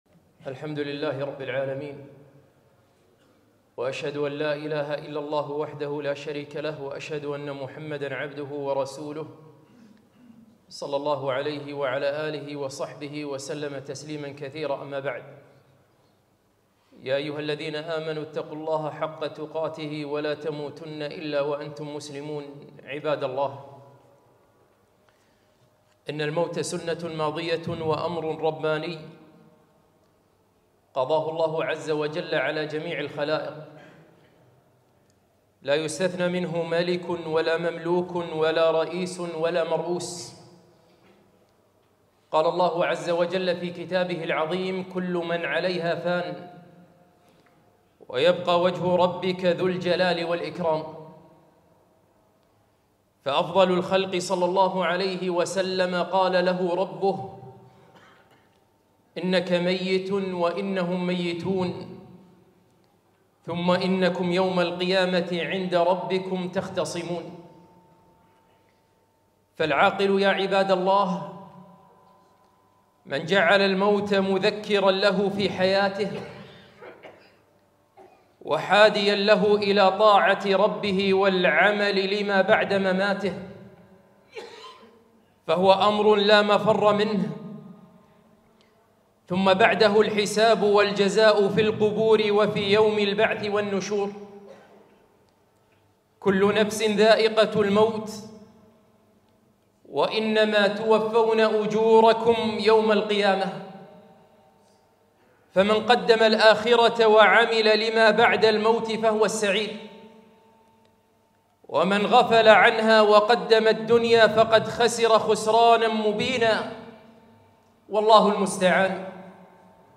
خطبة - وفاة أمير البلاد